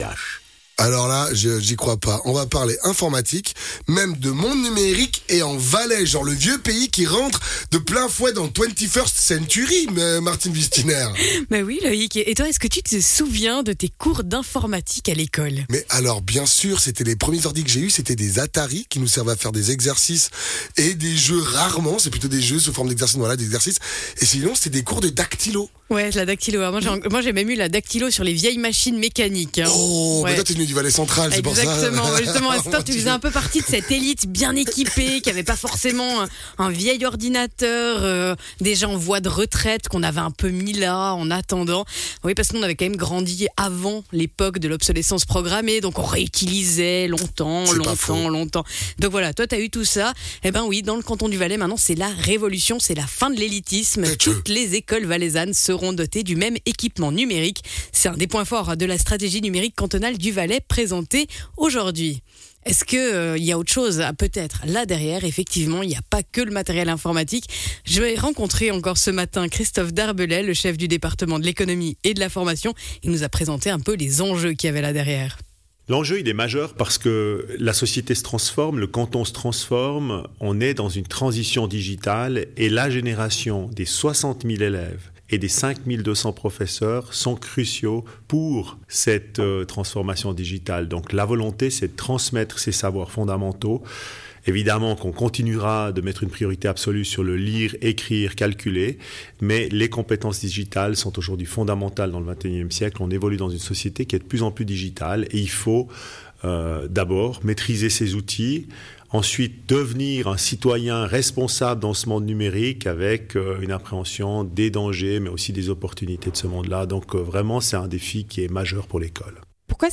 Intervenant(e) : Christophe Darbellay, chef du Département de l’économie et de la formation